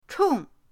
chong4.mp3